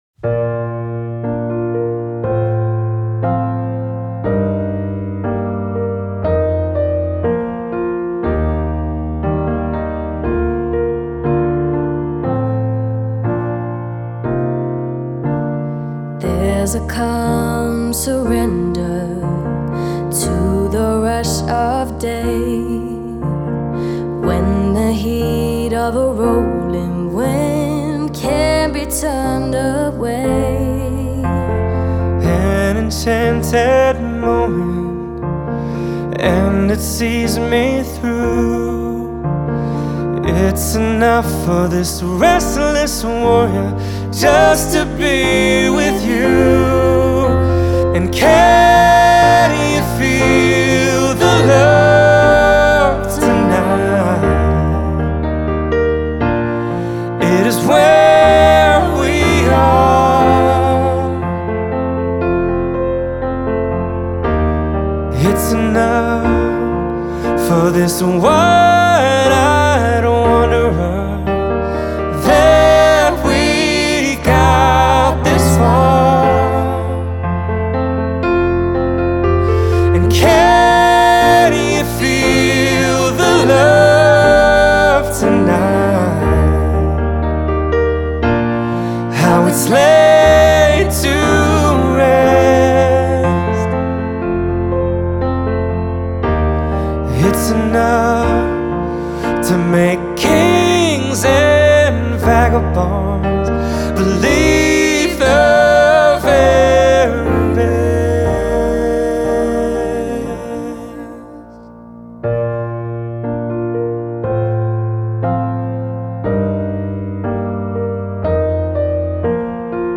Genre: Pop-Rock, Soft-Rock, Acoustic.